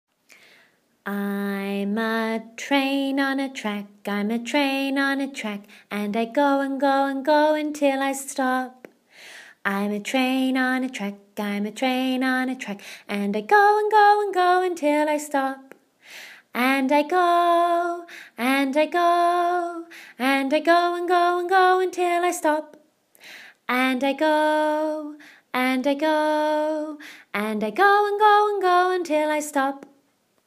Oo Sound - Train